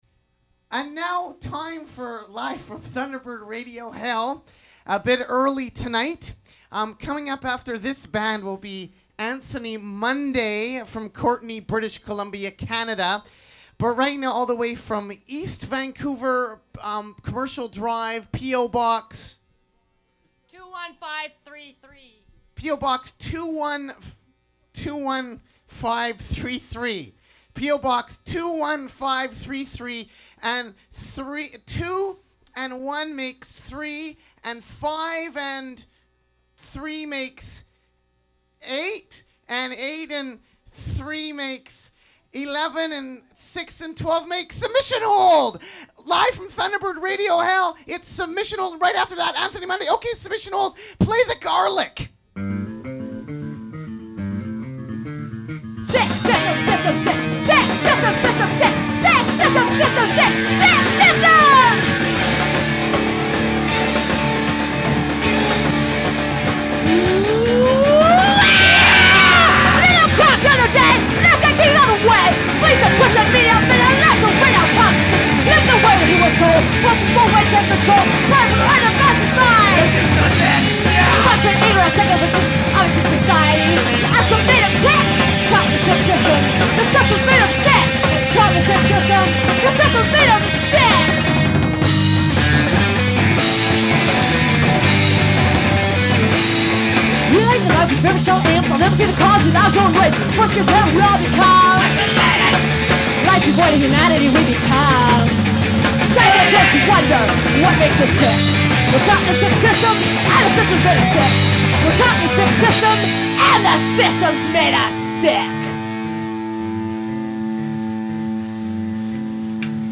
Recording of a live performance